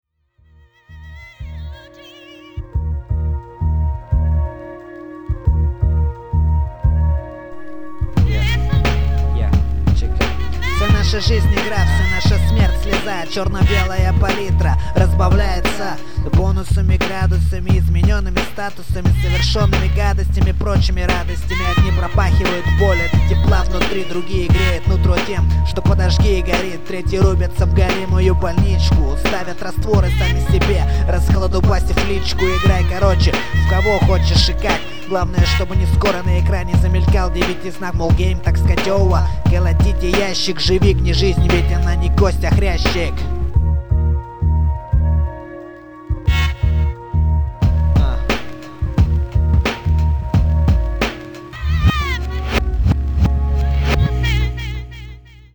2006 Рэп
Треки на различные баттлы...